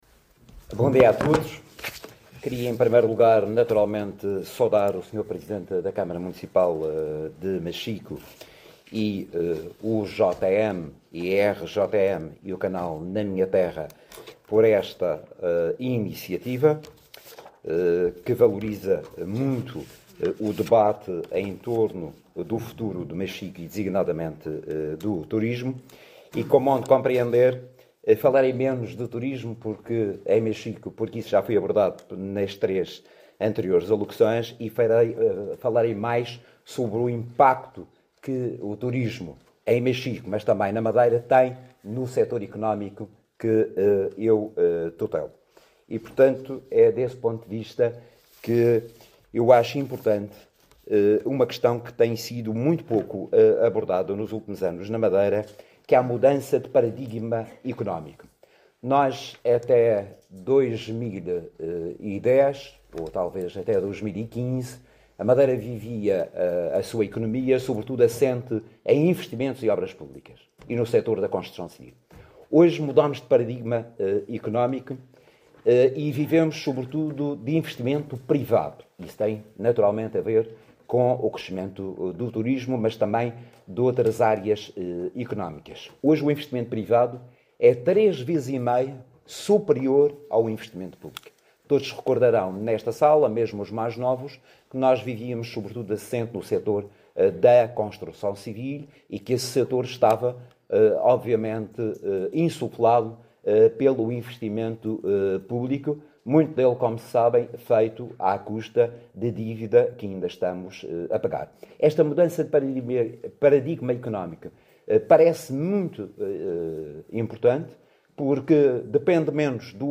Na intervenção que antecedeu o debate, José Manuel Rodrigues destacou que a economia madeirense vive atualmente um “novo paradigma”, assente sobretudo no investimento privado, muito impulsionado pelo turismo, pelas áreas tecnológicas, pelo imobiliário e pelo Centro Internacional de Negócios (CINM) sediado no concelho de Machico.